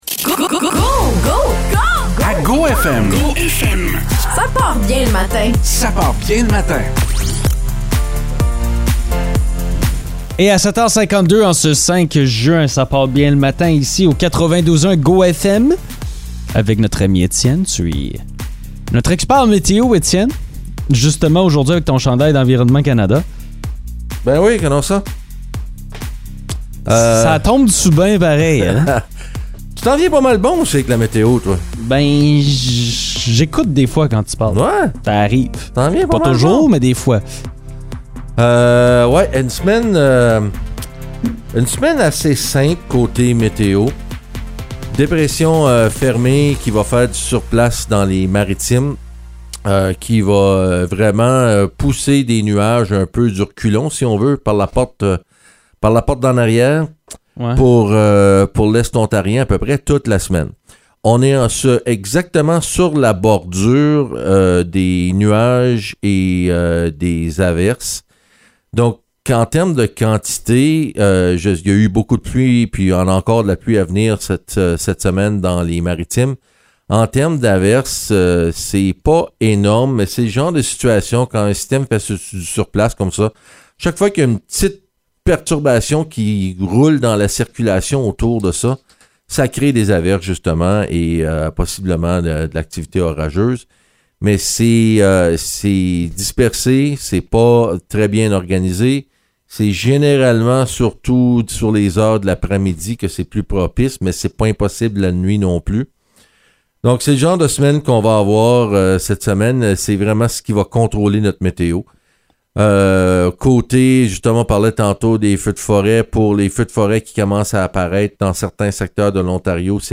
Chronique météo